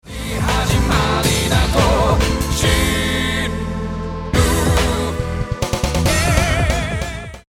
現状、コーラスを撮り直していないので、この様になっていますが、勿論本番までには入れ直しておきます。